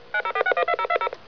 Descarga de Sonidos mp3 Gratis: telefono 10.